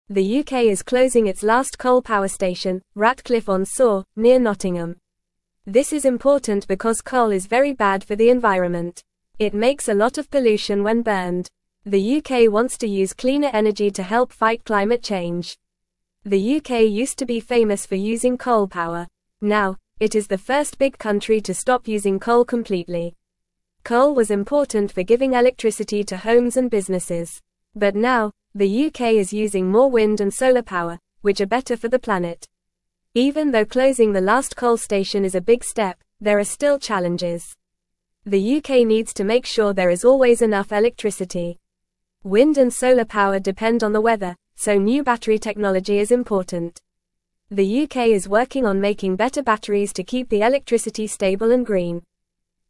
Fast
English-Newsroom-Lower-Intermediate-FAST-Reading-UK-Stops-Using-Coal-for-Electricity-Fights-Climate-Change.mp3